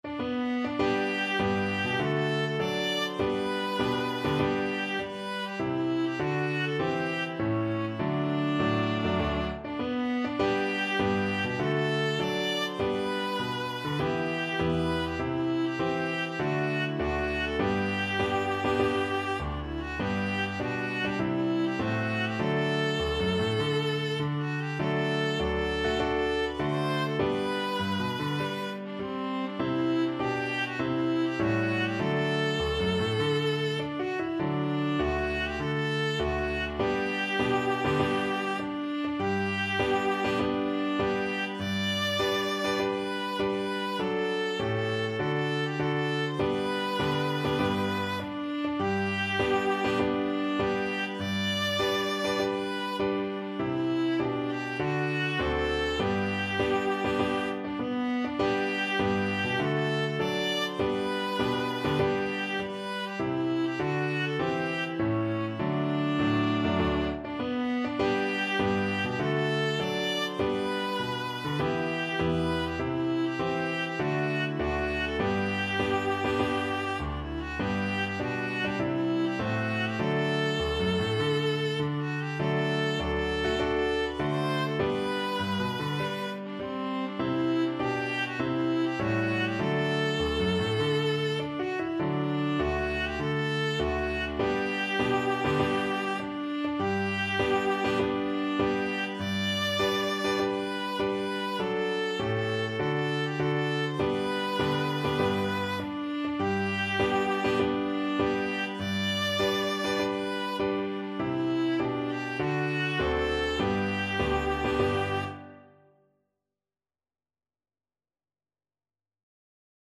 March =c.100